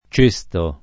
cesto [ ©%S to ]